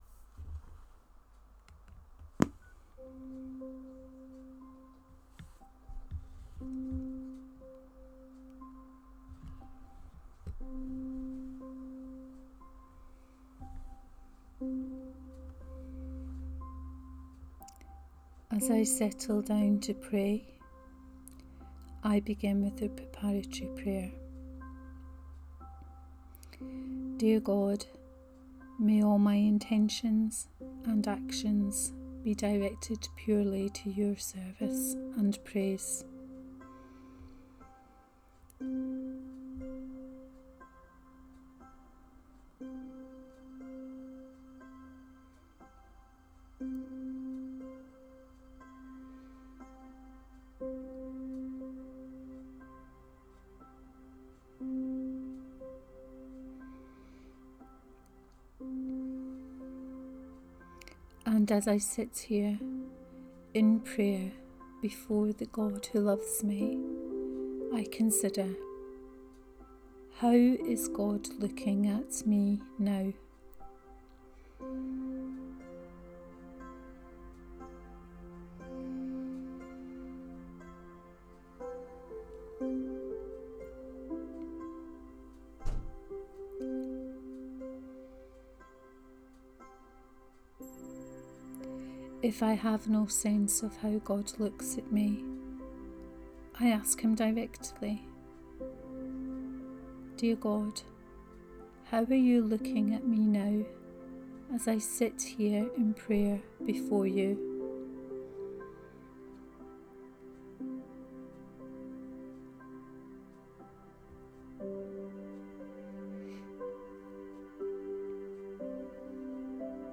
Nineteenth Sunday in Ordinary Time, Cycle A Here, as stated before, it is my intention to draw from the forthcoming Sunday liturgy and to offer a guided prayer on one of the pieces of scripture in …